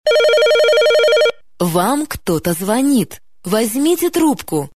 • Качество: 128, Stereo
голосовые